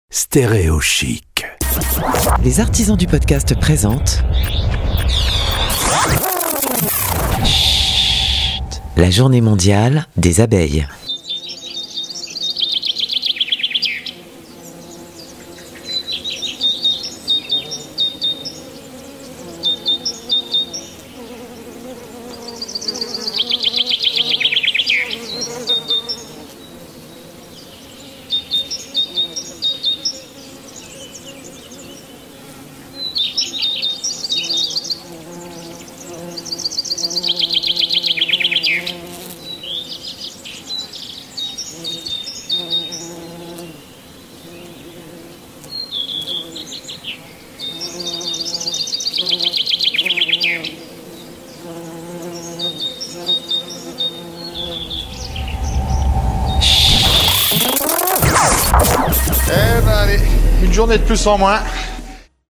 A l'occasion de la Journée Internationale des Abeilles, le 20 Mail 2021, voici une immersion de 60 secondes avec Schhhhhht produit par les Artisans du Podcast.